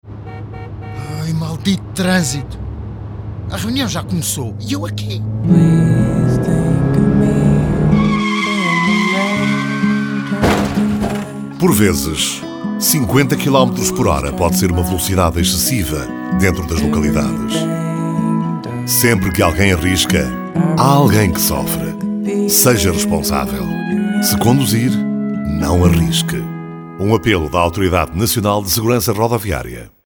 Spot Rádio: